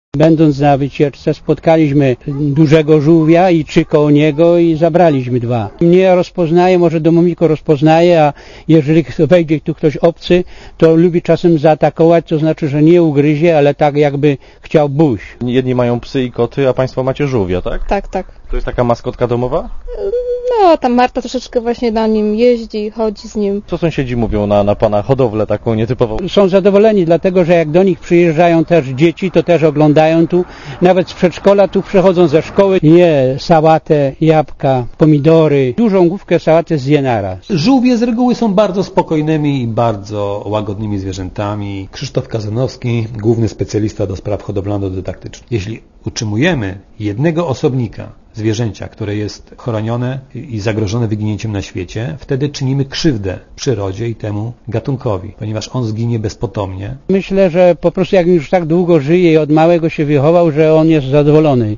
Komentarz audio (244Kb)